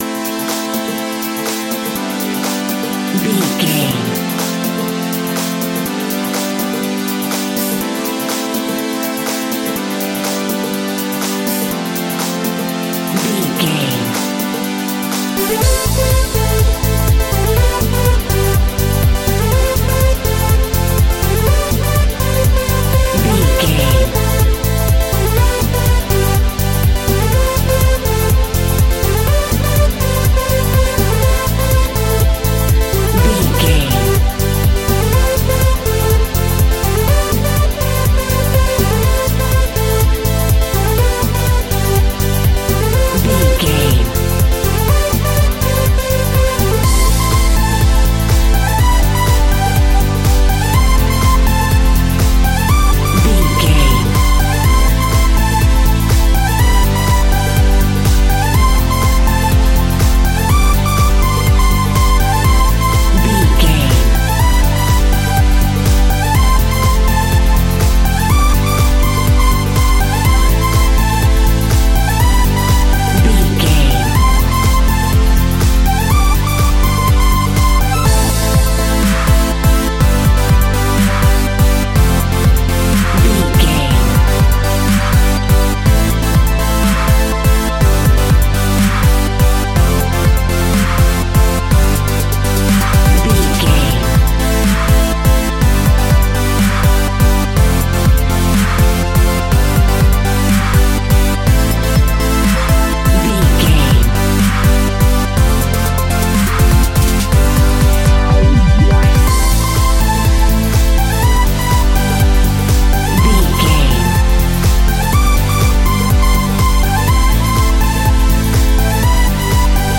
Aeolian/Minor
aggressive
powerful
dark
funky
groovy
futuristic
driving
energetic
drum machine
synth drums
synth leads
electronic music
techno music
synth bass
synth pad
robotic